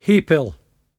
[hAY-pl]